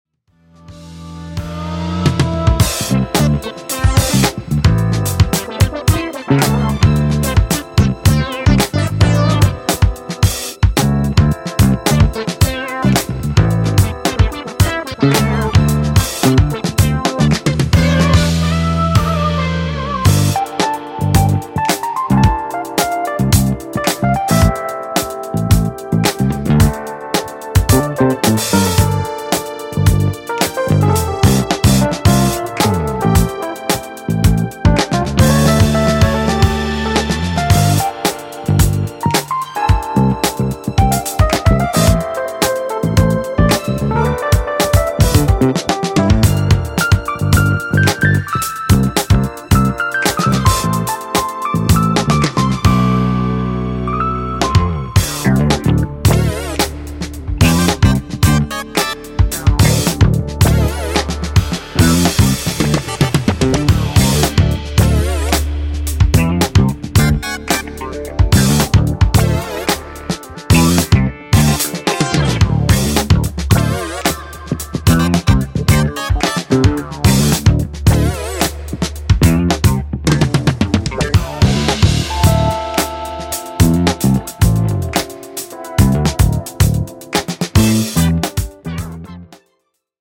this time of the Jazz-Funk variety
maintains an unwavering dance floor allure